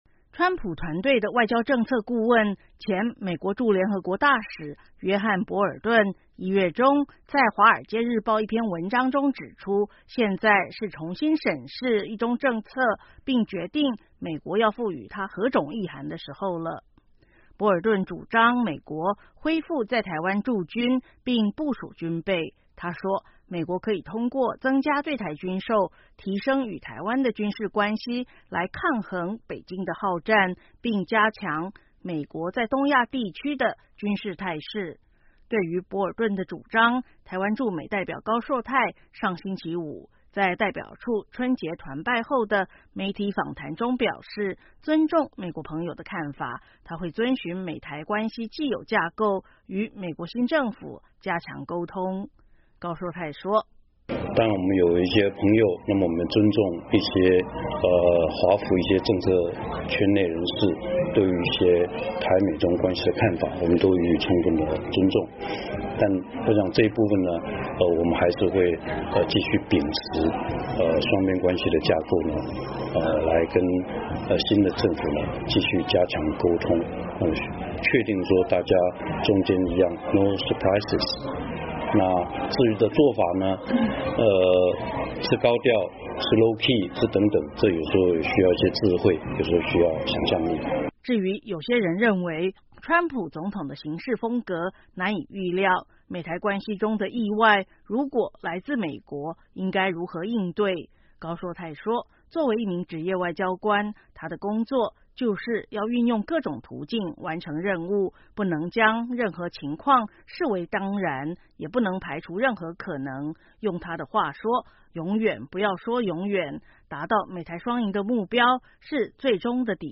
对于博尔顿的主张，台湾驻美代表高硕泰上星期五在代表处春节团拜后的媒体访谈中表示，“尊重”美国朋友的看法，他会遵循美台关系既有架构与美国新政府加强沟通。